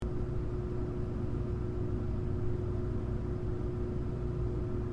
hum.mp3